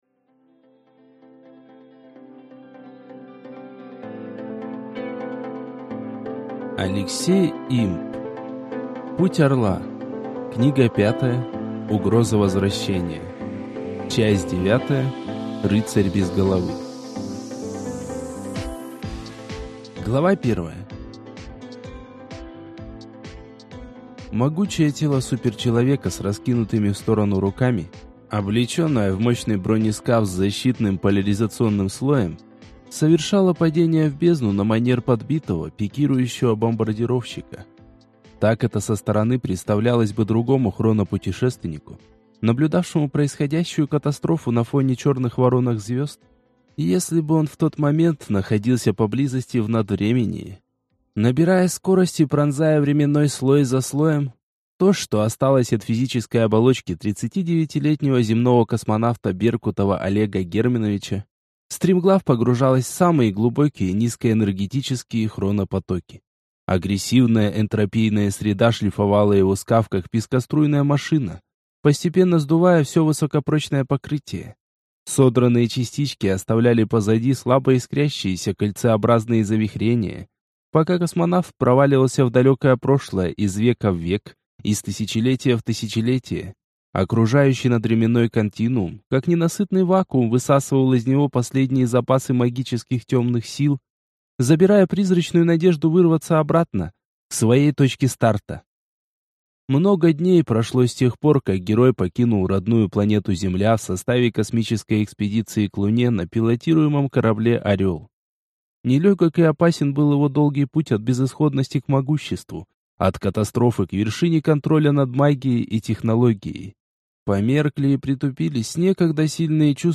Аудиокнига Путь Орла. Книга 5. Угроза возвращения | Библиотека аудиокниг